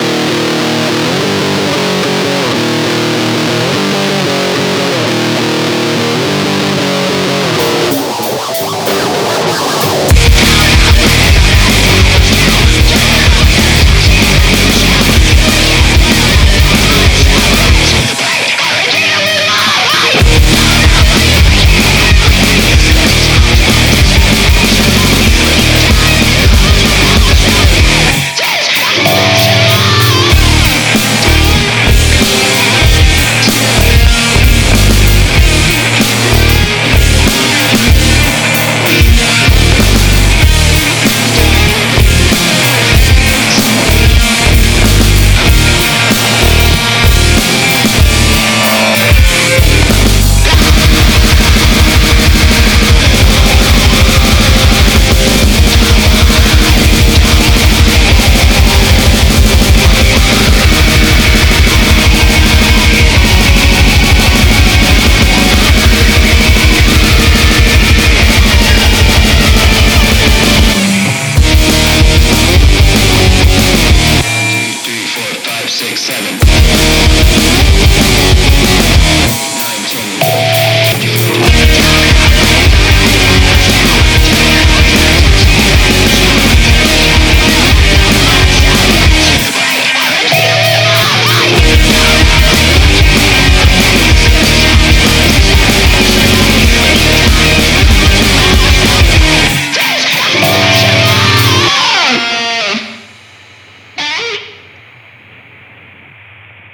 punk, metal, hardcore, grindcore, noise, ,